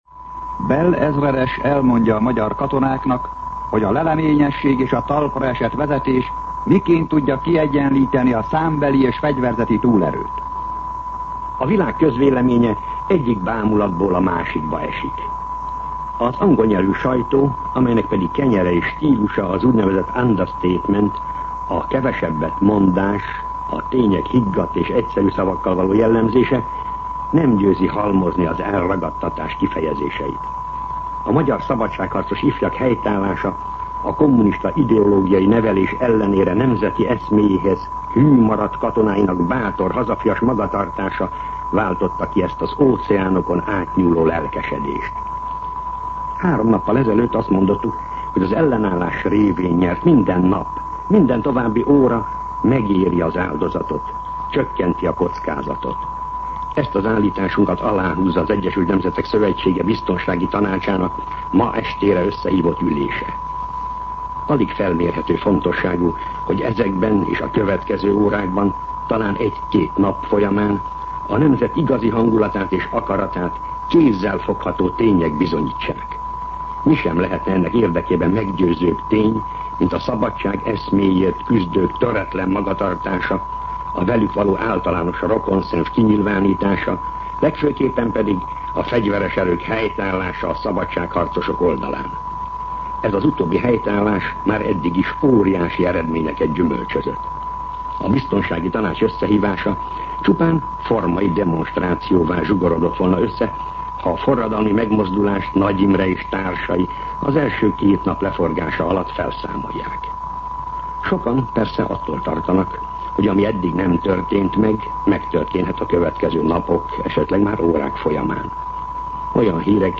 Katonapolitikai kommentár
MűsorkategóriaKommentár